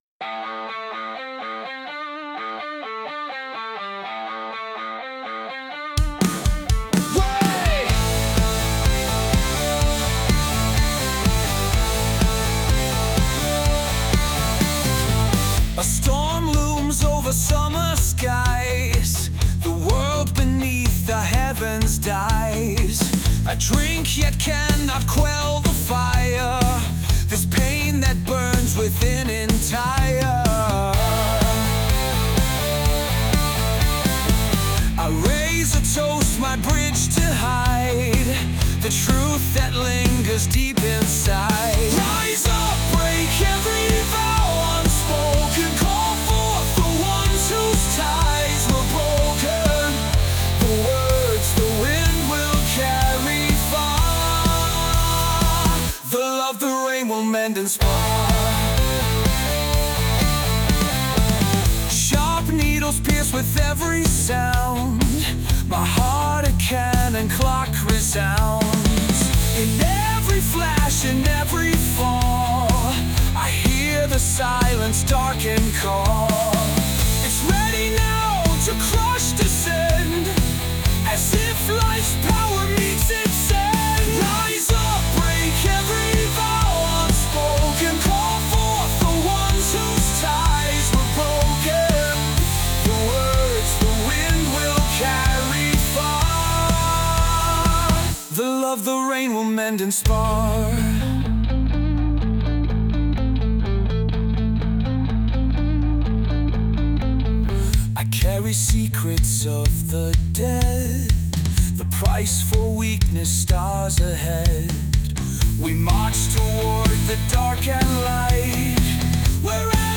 English, Pop, Rock, Metal | 18.03.2025 20:38